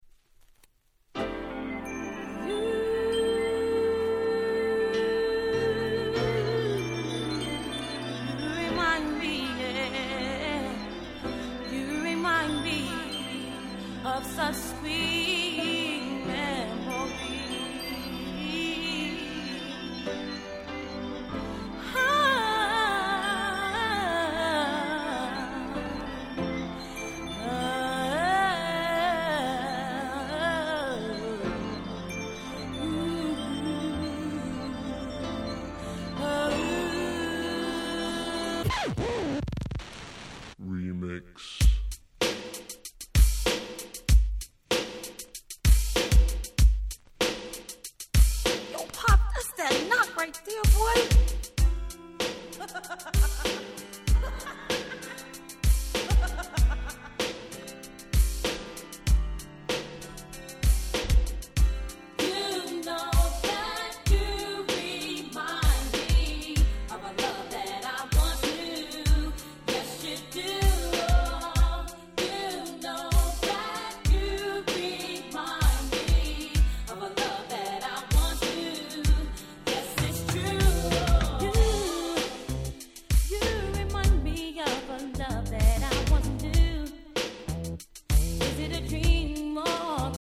91' US R&B Classic !!